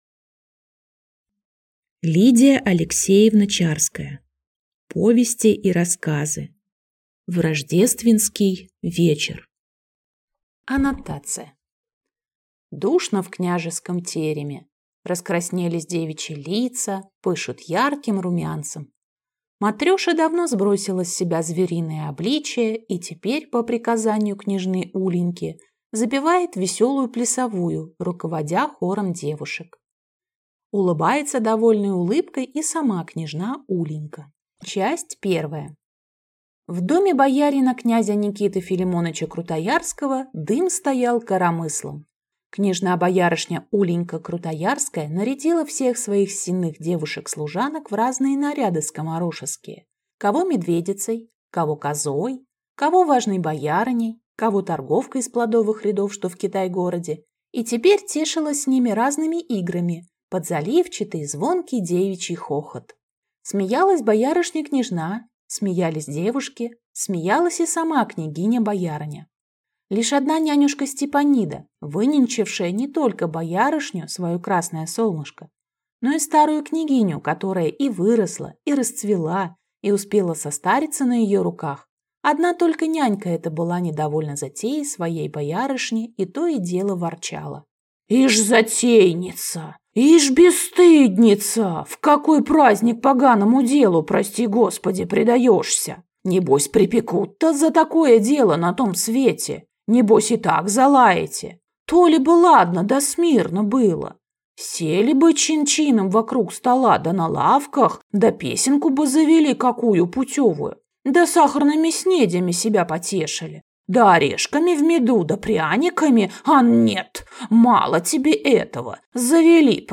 Аудиокнига В рождественский вечер | Библиотека аудиокниг